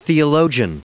Prononciation du mot : theologian
theologian.wav